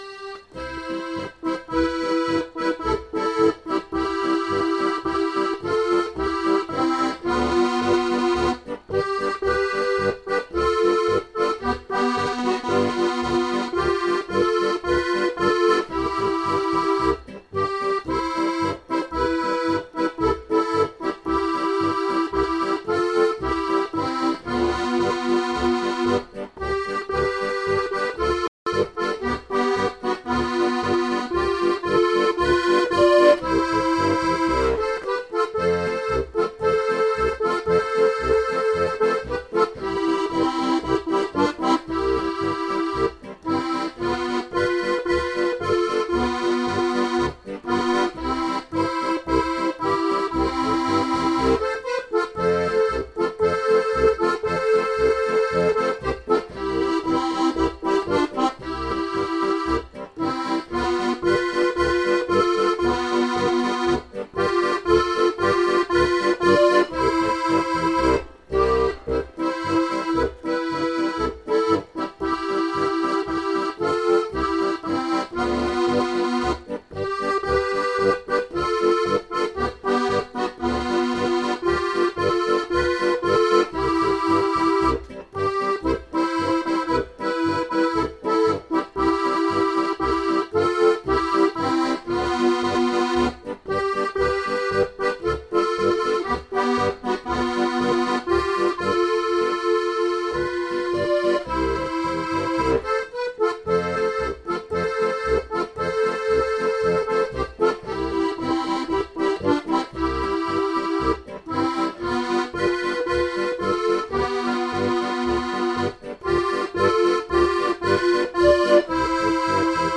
Ratespiel-Thread, Steirische-Harmonika-Edition
Ja, gern - warum nicht - es gibt keine geografischen Hinweise, keine Ahnung, wo das Stück herkommt Kommt aber auf jeden Fall aus der Blasmusik-Ecke - und sollte einigermaßen bekannt sien Anhänge SPHarmonikaboard.mp3 3,6 MB